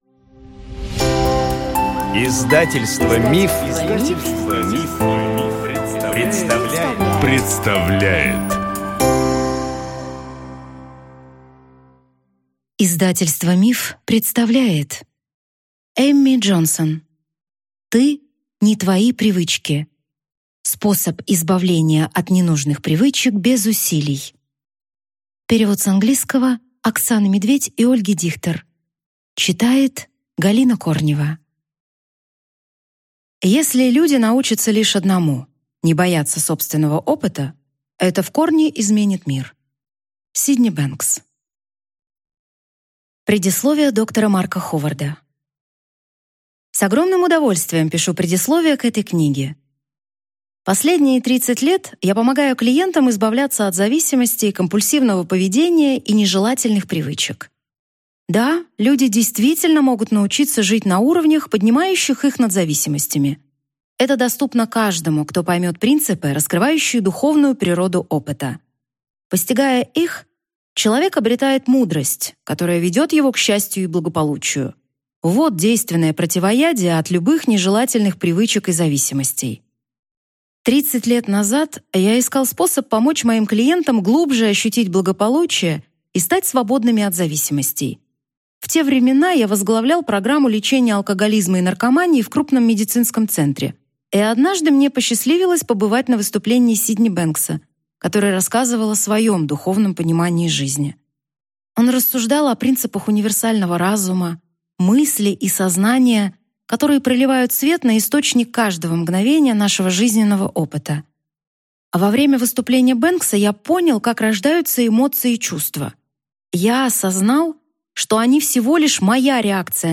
Аудиокнига Ты – не твои привычки. Способ избавления от ненужных привычек без усилий | Библиотека аудиокниг